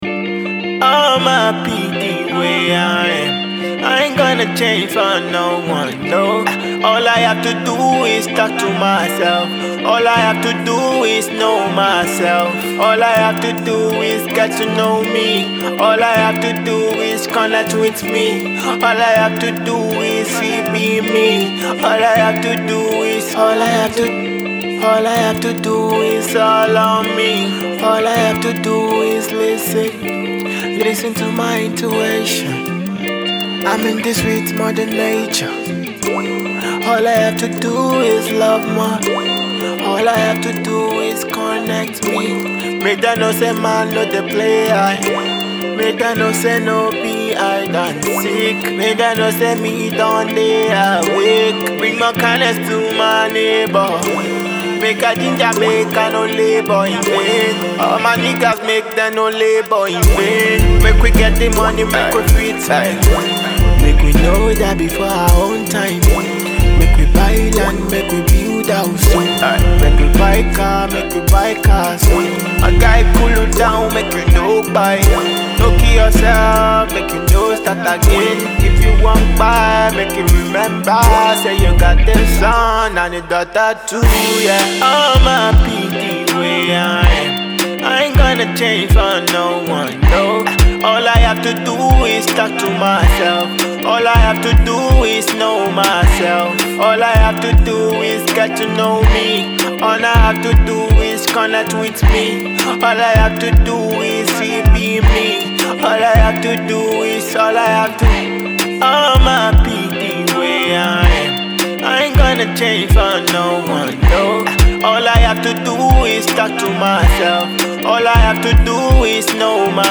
Soulful and Afrocentric
mellifluous tone